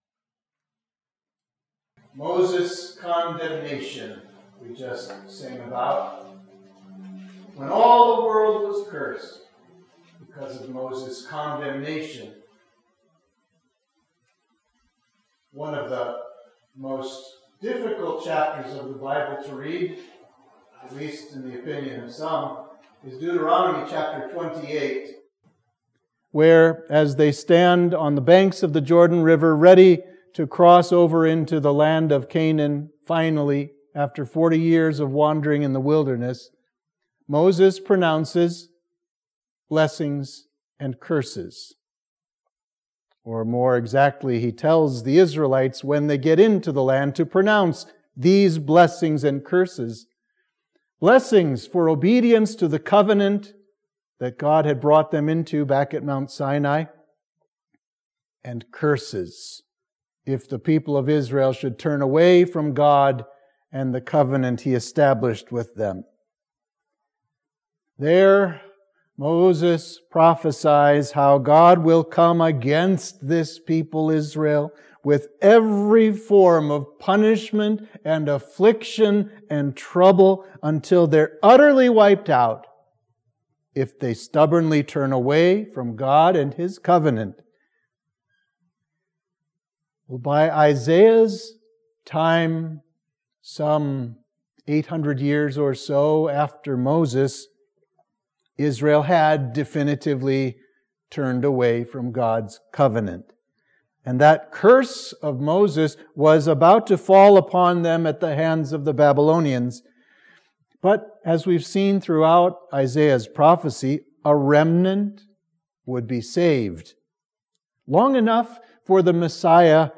Sermon for Midweek of Trinity 20